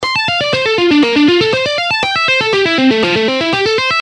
This hybrid picking style combines the best of alternate picking and sweep picking, enabling you to play scales, arpeggios, and complex runs with smoothness and precision.
economy-picking-lesson.gpx-5.mp3